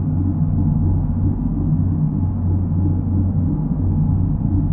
drone_ship_2.WAV